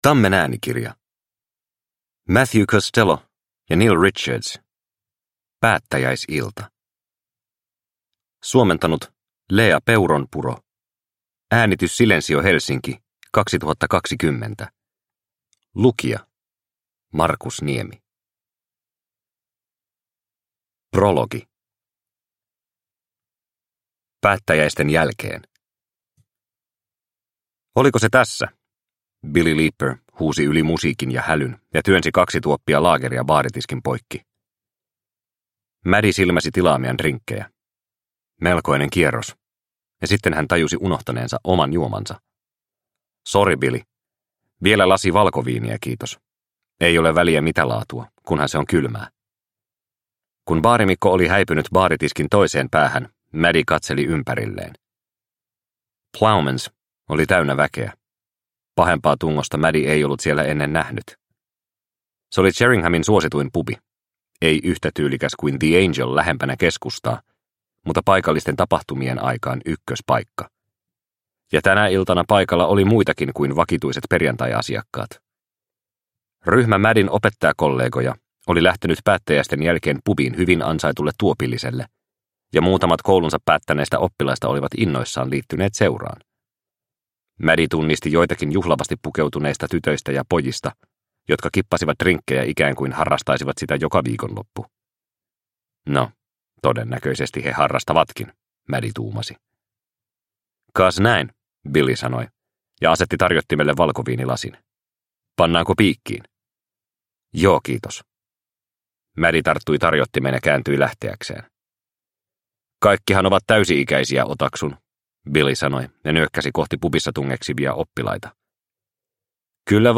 Päättäjäisilta – Ljudbok – Laddas ner